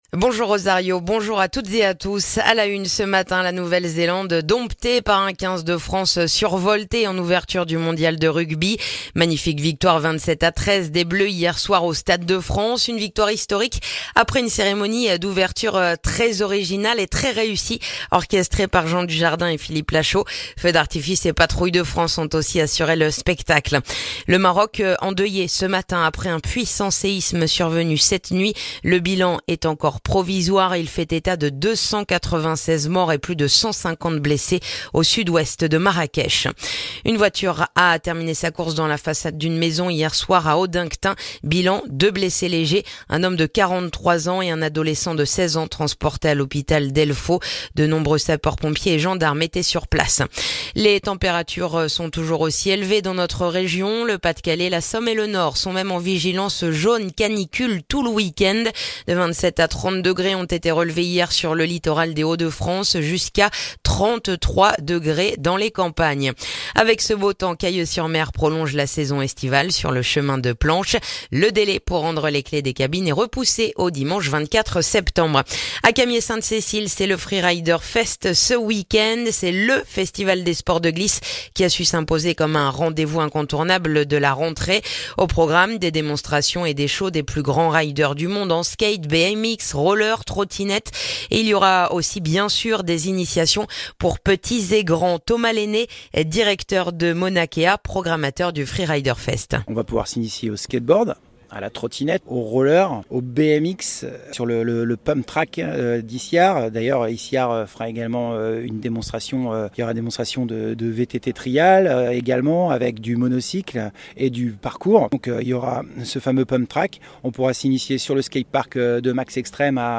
Le journal du samedi 9 septembre